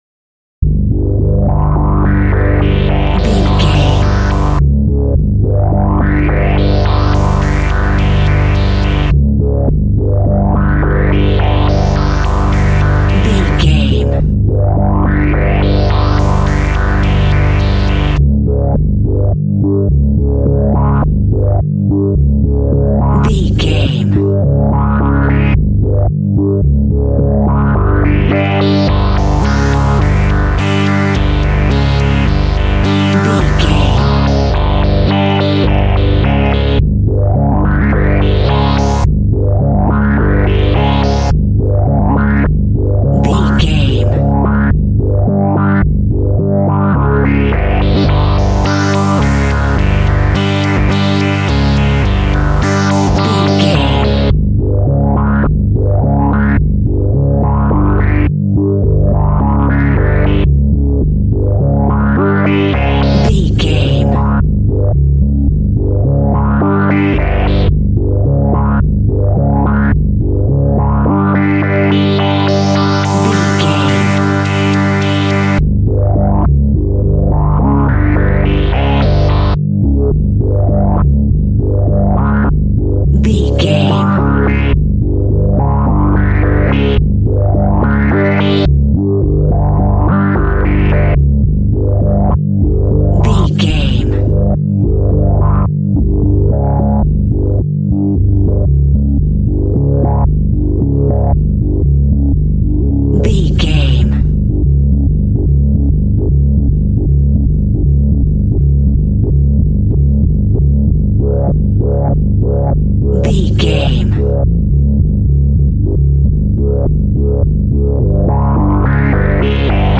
Aeolian/Minor
scary
tension
ominous
dark
suspense
eerie
synthesiser
Horror synth
Horror Ambience
electronics